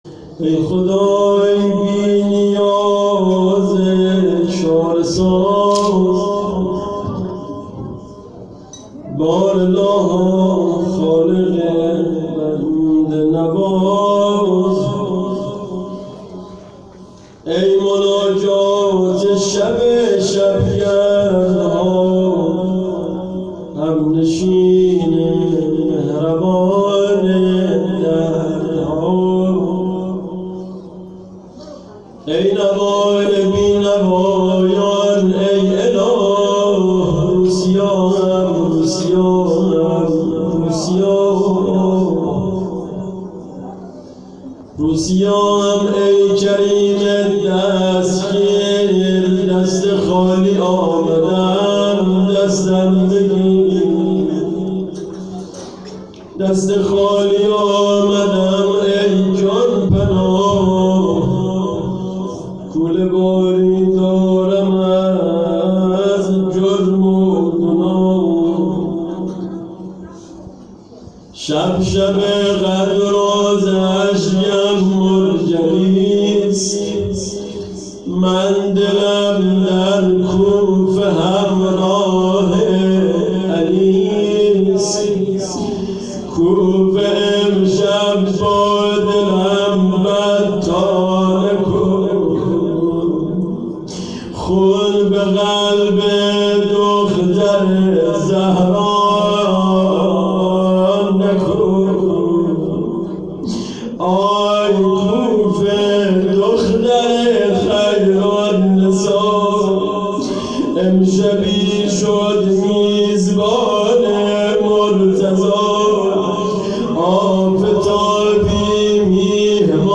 خیمه گاه - هیئت محبان المهدی(عج)آمل - شب 19 ماه رمضان_بخش دوم روضه